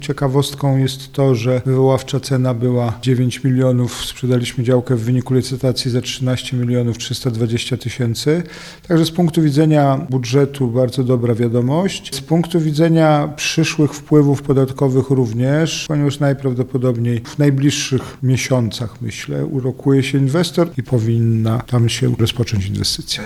– Po kilku miesiącach negocjacji uzyskaliśmy wysoką cenę tego gruntu. Być może z tego powodu, że nie mamy już atrakcyjnych działek – powiedział prezydent Jacek Milewski: